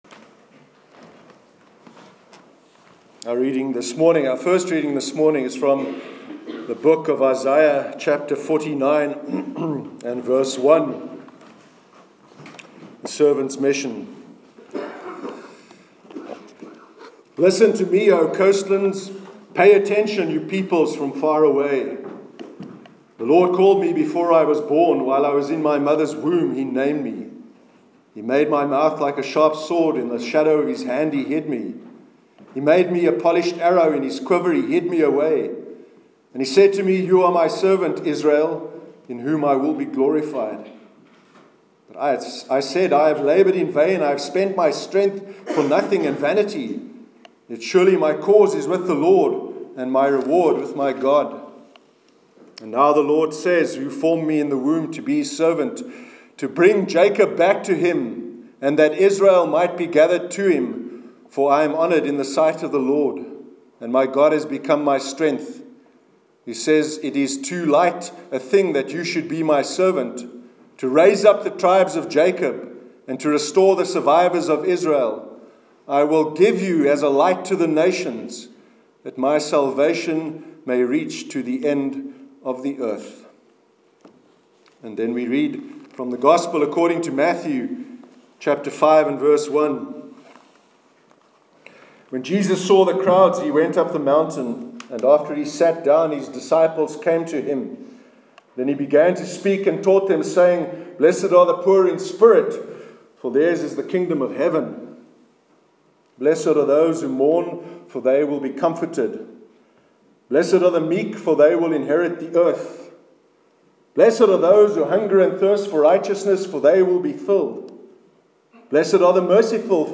Sermon on the Light of the World- 29 Jan 2017